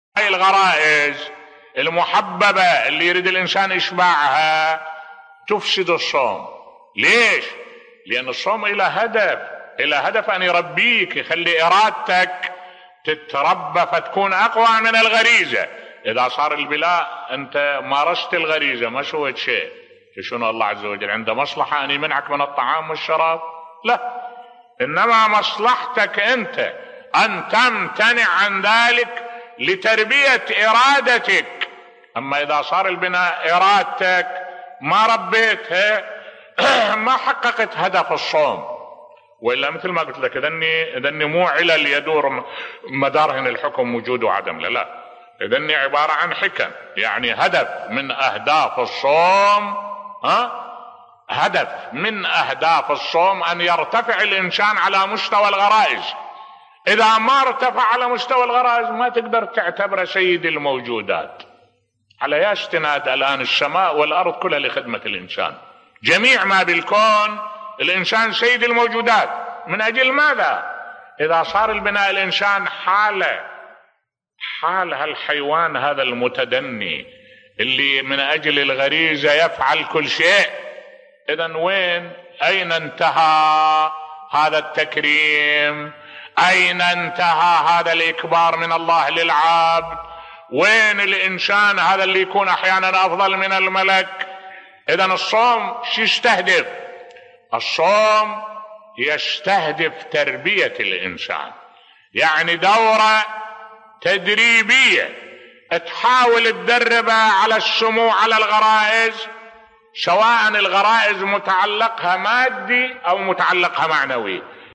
ملف صوتی ما الأشياء التي تفسد و تبطل الصوم بصوت الشيخ الدكتور أحمد الوائلي